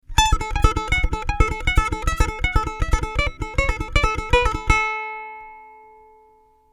People often find this technique easier to play since the second note can be made to ring louder right out of the gate.
The Gist: Pick a fretted note then pull your finger off the note to sound the note behind it.
pull-offs-up-and-down-one-string.mp3